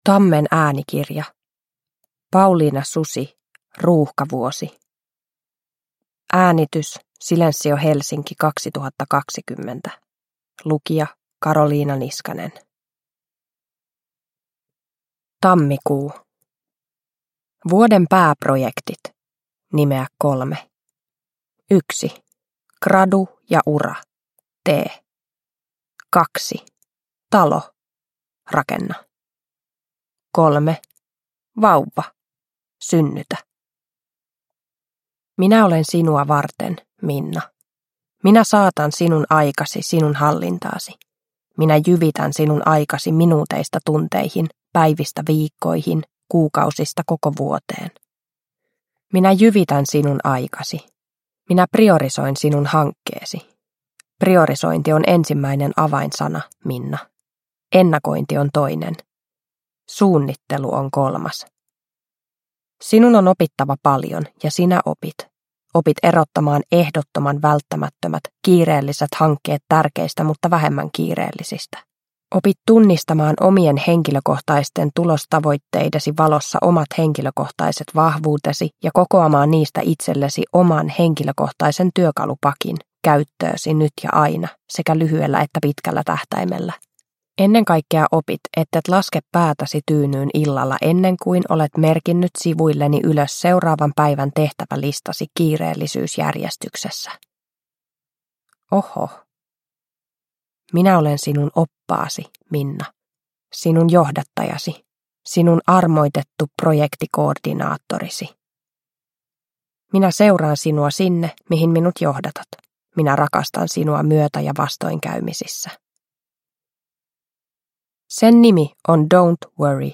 Ruuhkavuosi (ljudbok) av Pauliina Susi